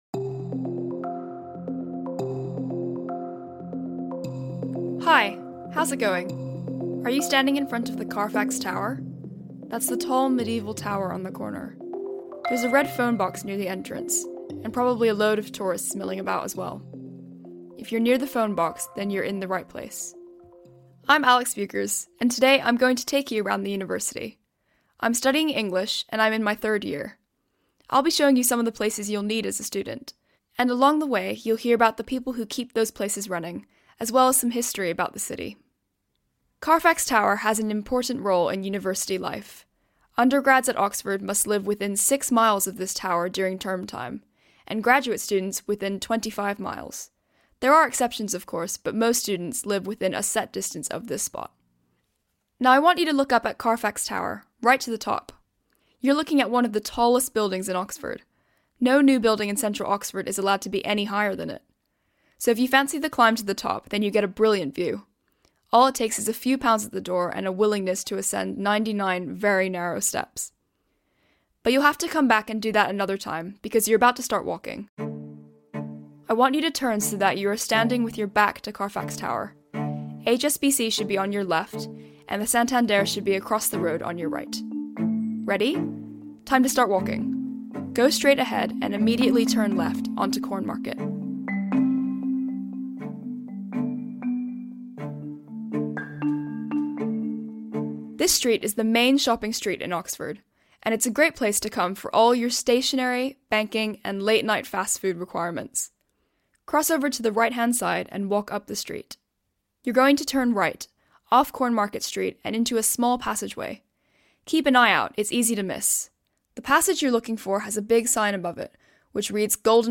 Know Your Oxford is an audio tour of the city of Oxford, beginning at Carfax Tower on the corner of Queen Street and Cornmarket, and ending at Magdalen Bridge. Winding through the historic heart of Oxford, the tour passes by iconic buildings including the Radcliffe Camera and the Sheldonian Theatre, revealing snippets of the city’s long history as it goes.